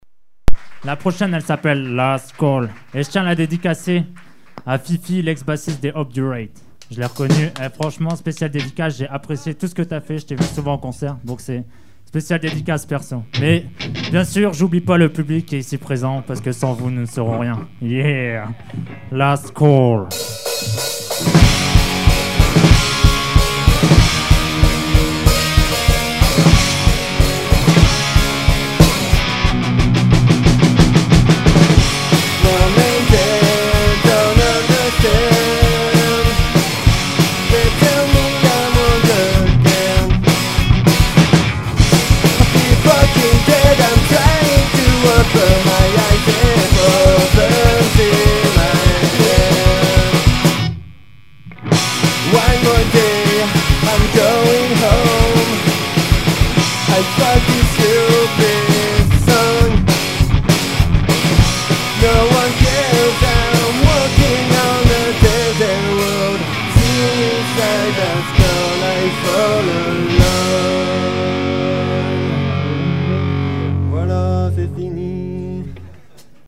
Live au Biplan